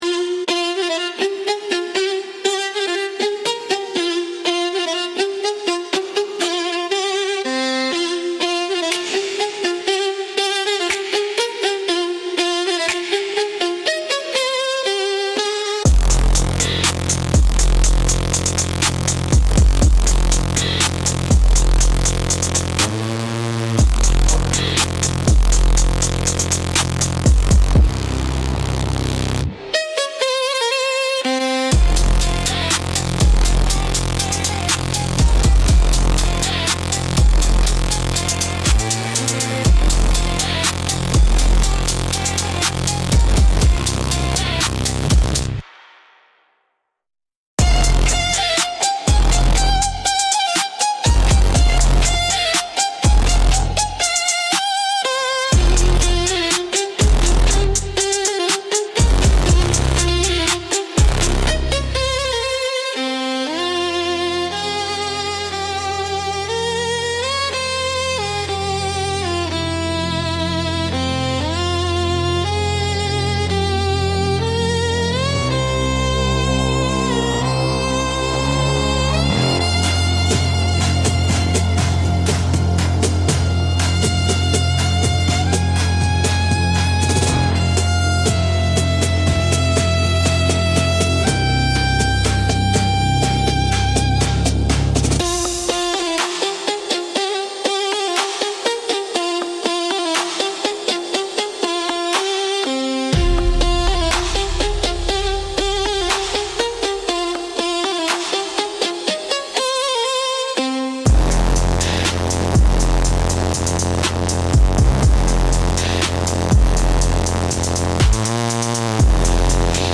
Instrumental - Rules Are Meant to Bend